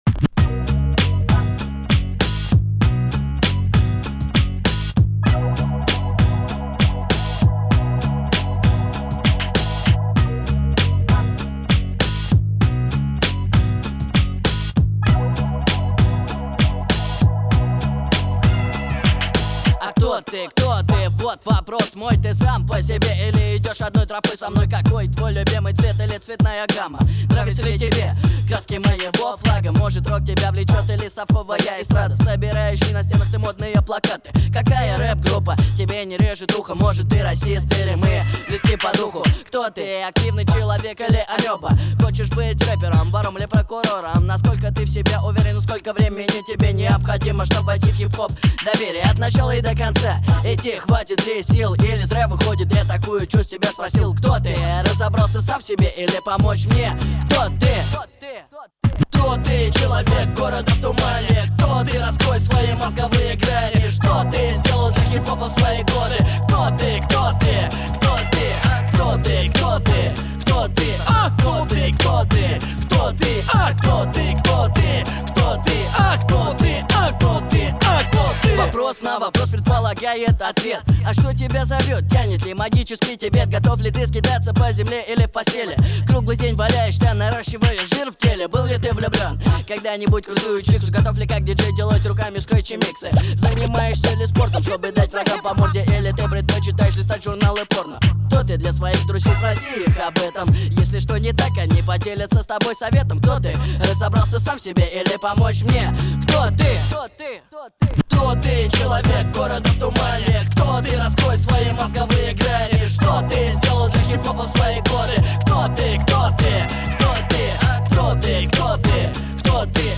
Жанр: Rap & Hip-Hop